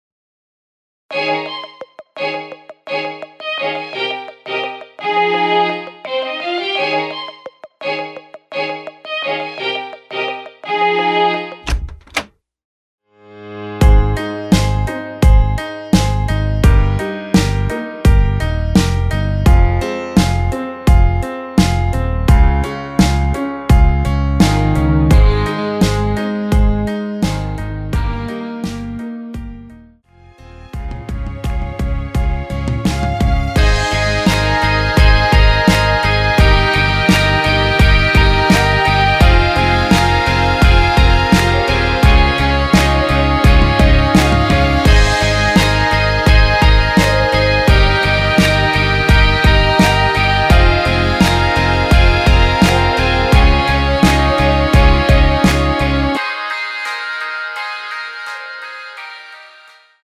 원키에서(+5)올린 MR입니다.
Ab
앞부분30초, 뒷부분30초씩 편집해서 올려 드리고 있습니다.